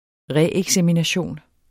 Udtale [ ˈʁε- ]